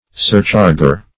Surcharger \Sur*char"ger\, n. One who surcharges.